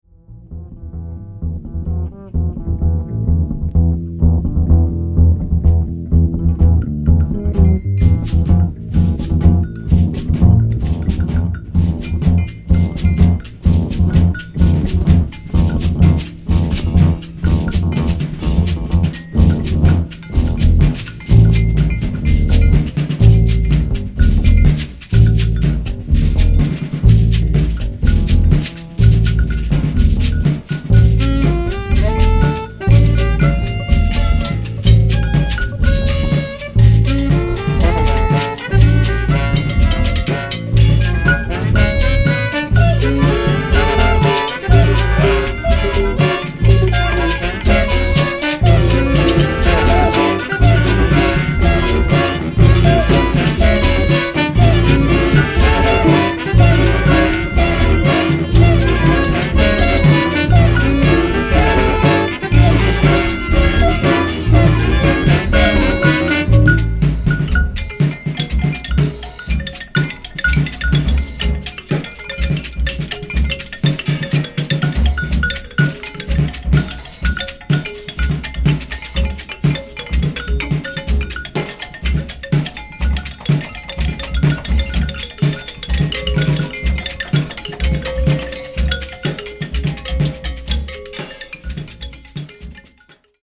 分厚い音の壁と沈黙とが迷宮状に配置された極めて構築的な仕上がり。